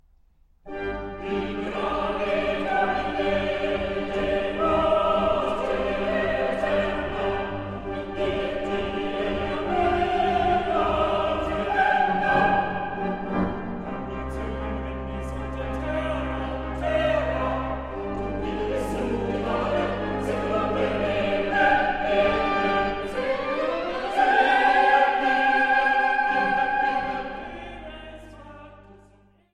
Orgel
eine Nelson-Orgel von 1904